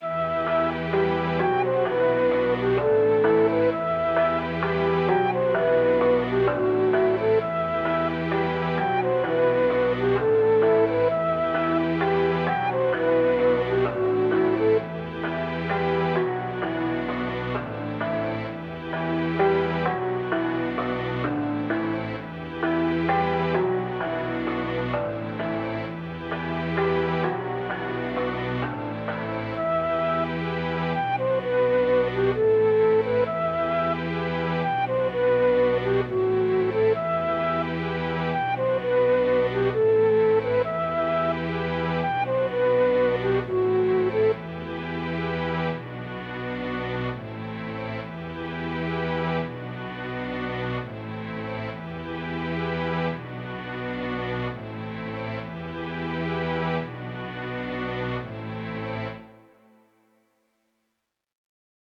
130BPM, E Minor